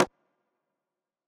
rim 12.wav